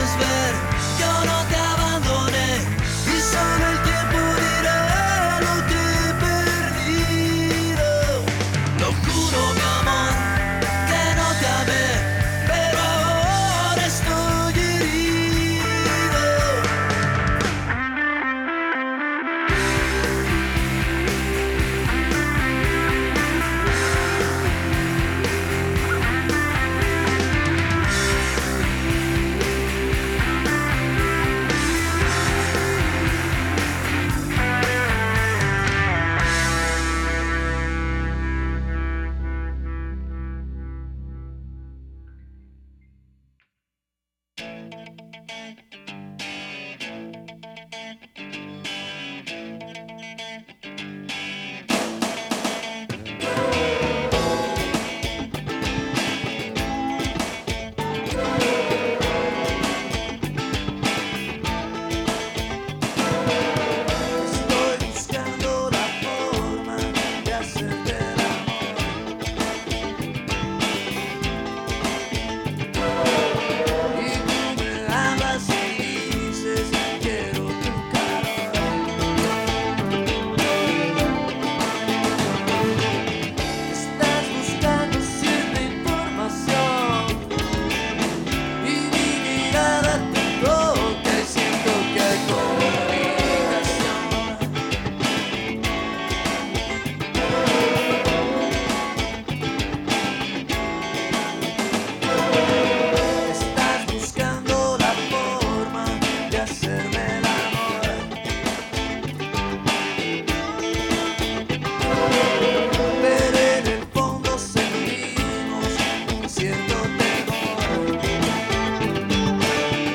With many official independent productions of poetic and musical weight he will be with us from his home in Guadalajara, Jalisco, Mexico, sharing experiences and introducing his songs.